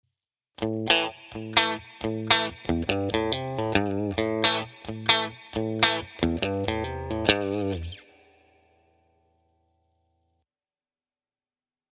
We’re using a slide,  a hammer-on and pull-off and add vibrato to the last note.